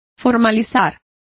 Complete with pronunciation of the translation of formalized.